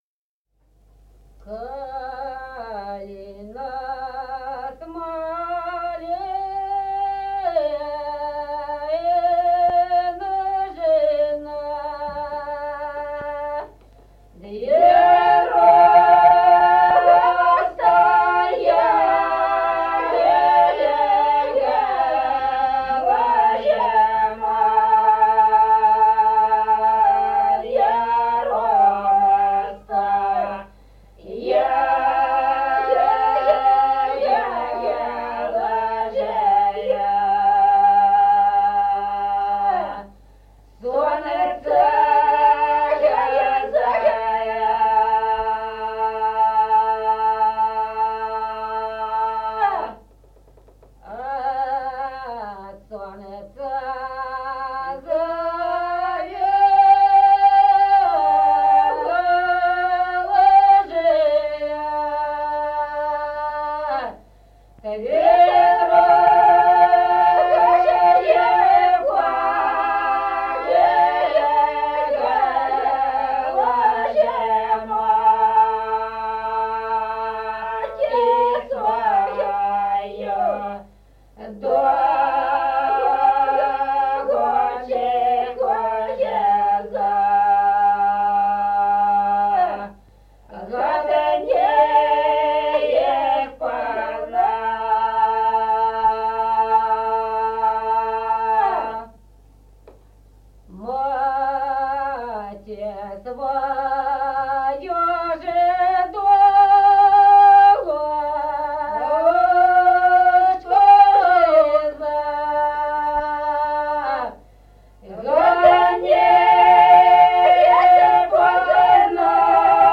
Песни села Остроглядово. Калина с малиной.